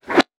weapon_bullet_flyby_18.wav